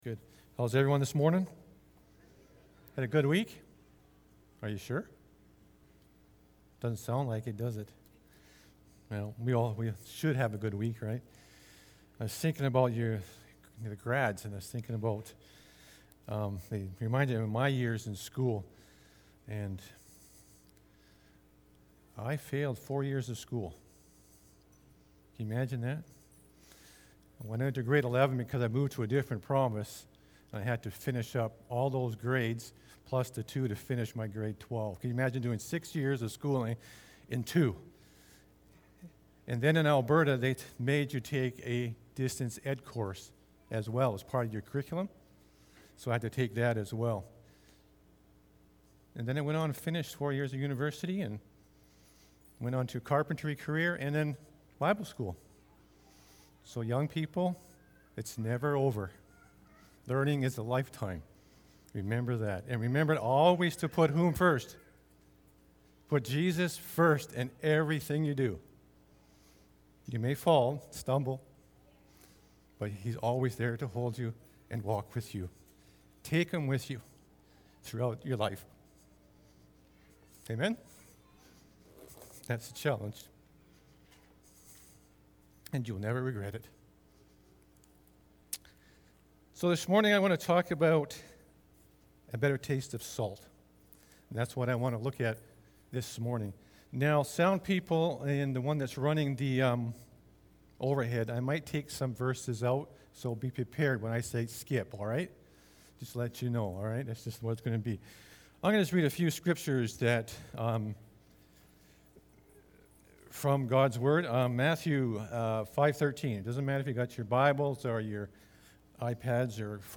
June-6-sermon-audio.mp3